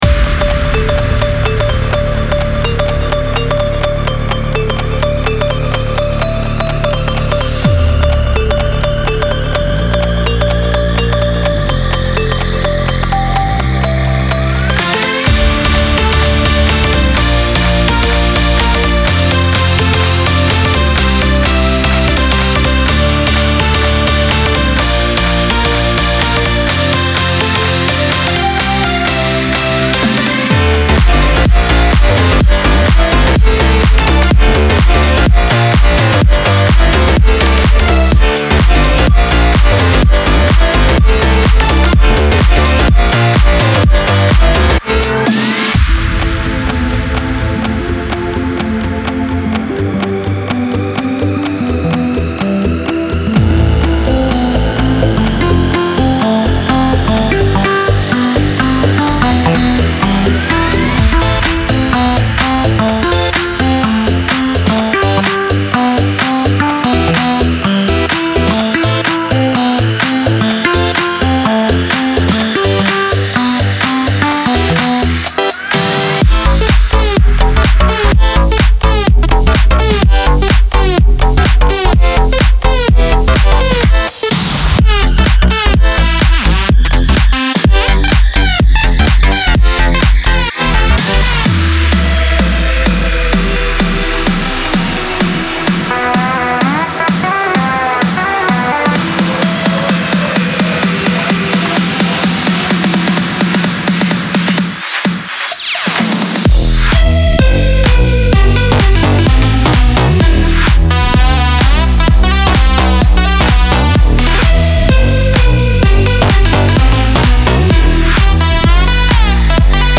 Progressive House
Tropical House